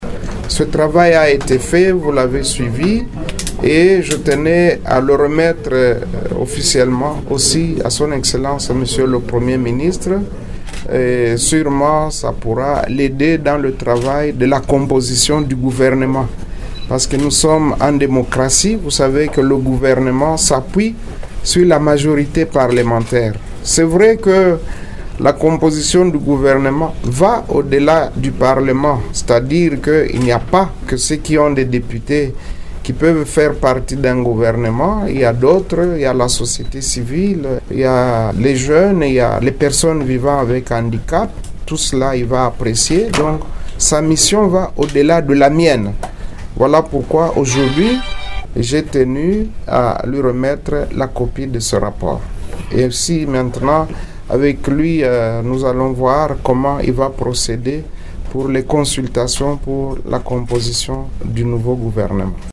Vous pouvez écouter Modeste Bahati dans cet extrait :